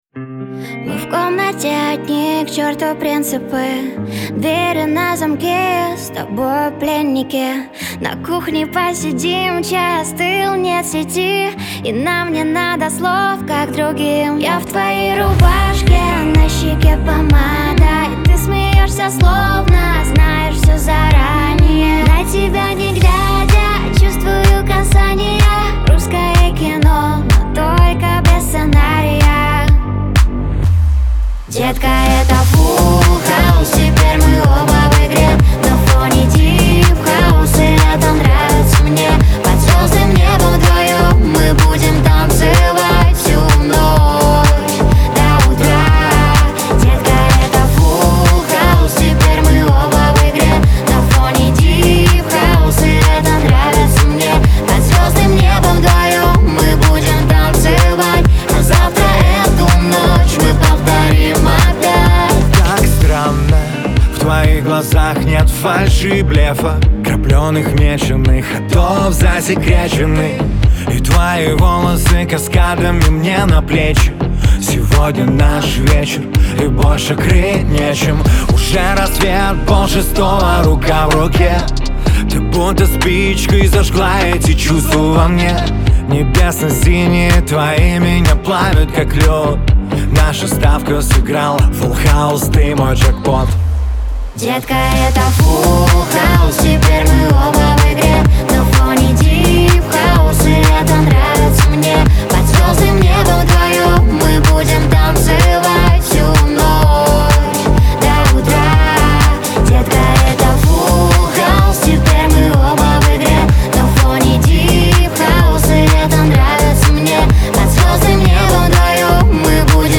дуэт
pop
эстрада